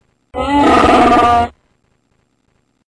Chewbacca sound
Kategorie: Meme-Sounds
Beschreibung: Lade dir jetzt den legendären Chewbacca Sound herunter und bringe das berühmte Brüllen des Wookiees direkt auf dein Handy oder deinen Computer!
chewbacca_sound-www_tiengdong_com.mp3